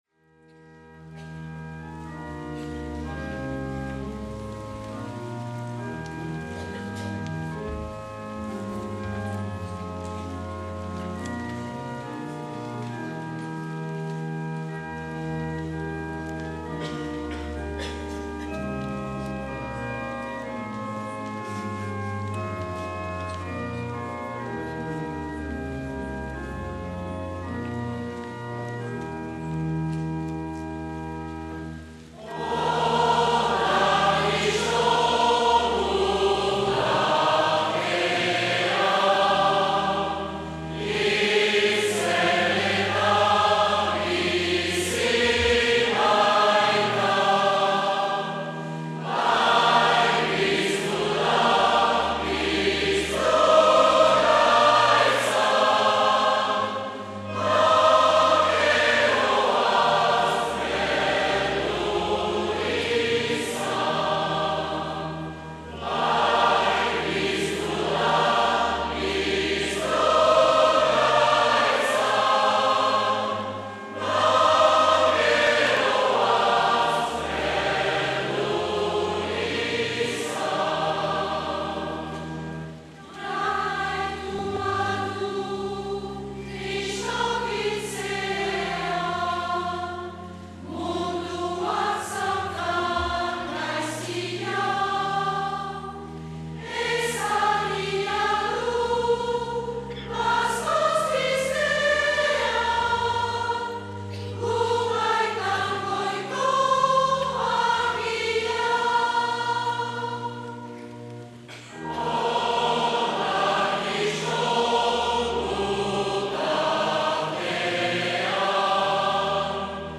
2020-04-26 Bazkoko 3. igandea A - Uztaritze